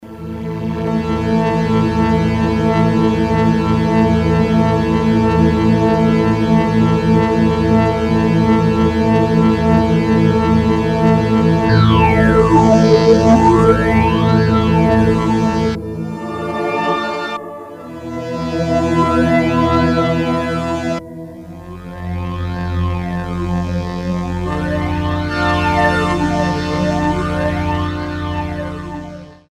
edit EFFECT a single built-in analog shift phaser with emphasis tremolo and frequency rate.
Phase shifting process is able to modulate 3 sections at the same time: ensemble, percussive and strings but NOT the choir section.
demo HEAR phaser